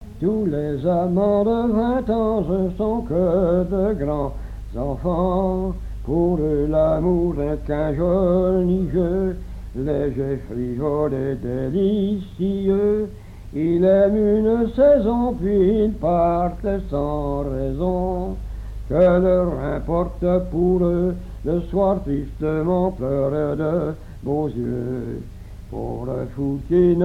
Témoignages sur le violoneux, airs à danser, chansons et un monologue
Pièce musicale inédite